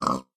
pig_say3.ogg